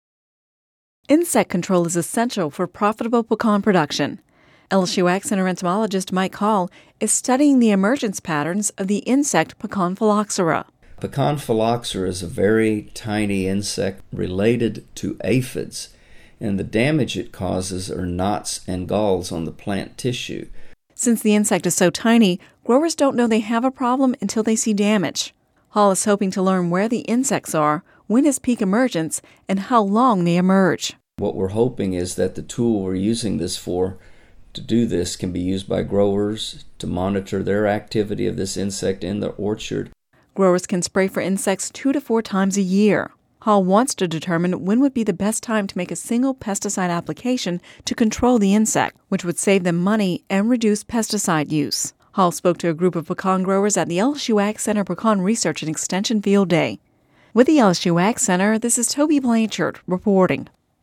(Radio News 06/14/10) Insect control is essential for profitable pecan production.